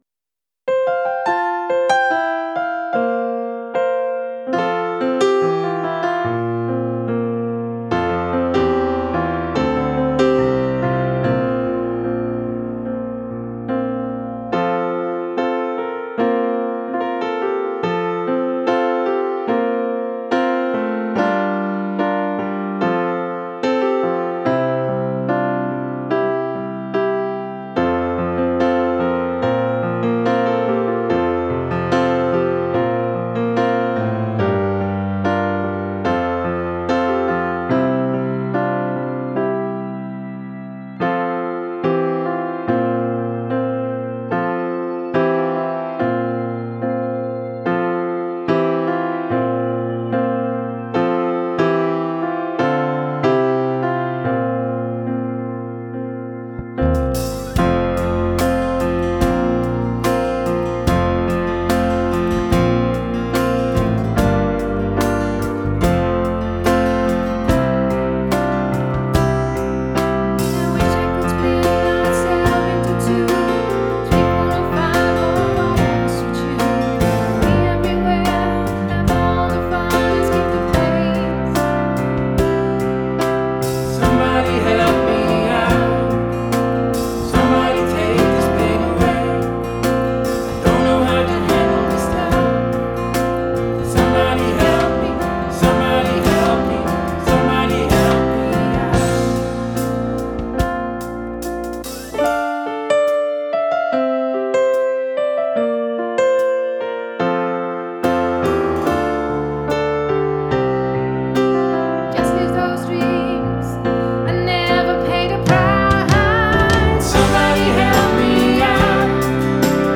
Drums
Basgitaar